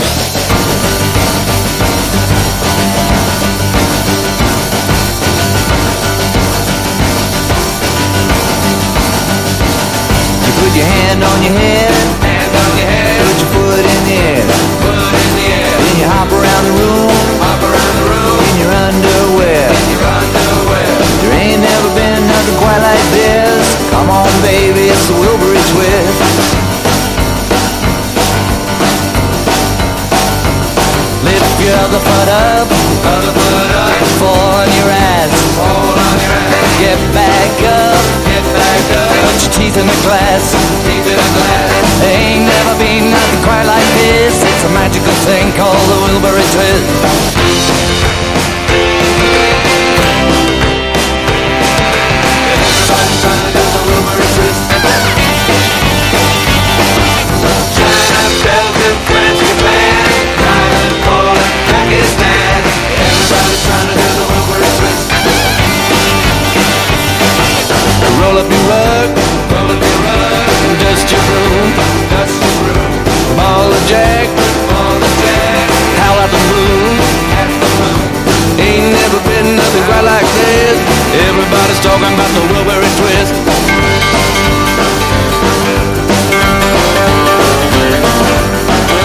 1. 90'S ROCK >
四人それぞれの持ち味を発揮したリード･ヴォーカルが最高！